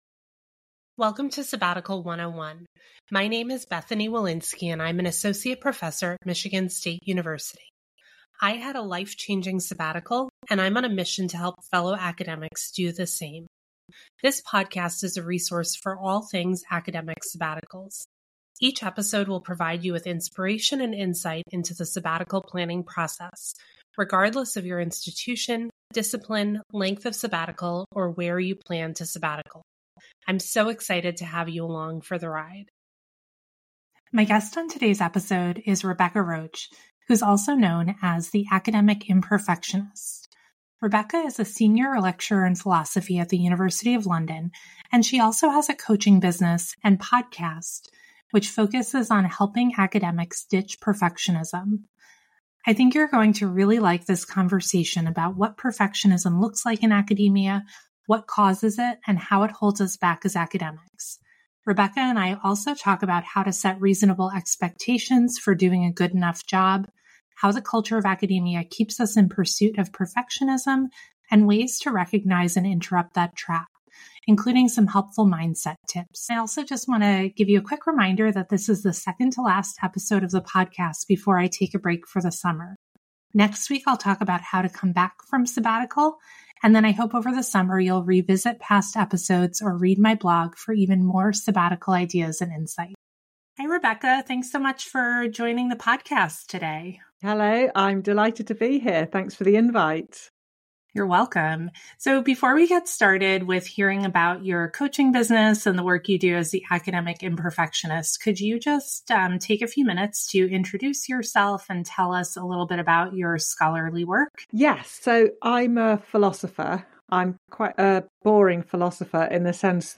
Join us for this conversation about what perfectionism looks like in academia, what causes it, and how it holds us back as academics. We also talk about how to set reasonable expectations for doing a good enough job, how the culture of academia keeps us in pursuit of perfectionism and ways to recognize and interrupt that trap, including helpful mindset tips.